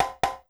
BAL Conga Hits.wav